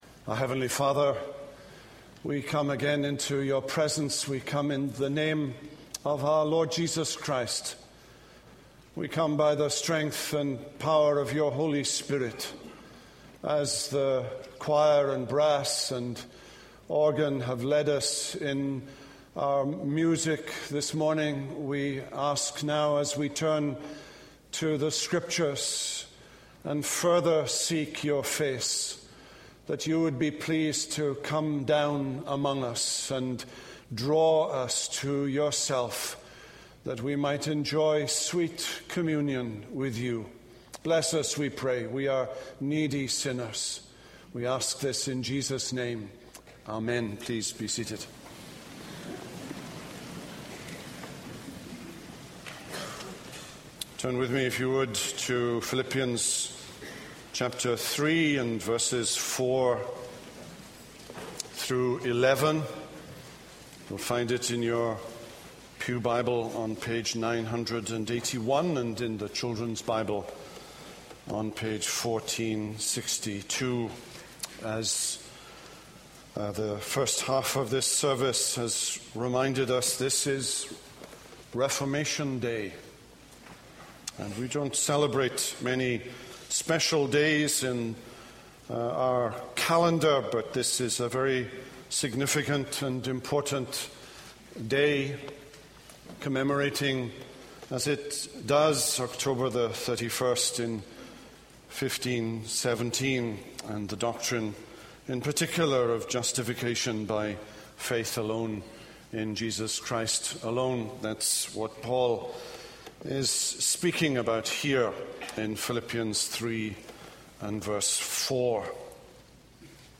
This is a sermon on Philippians 3:4-11.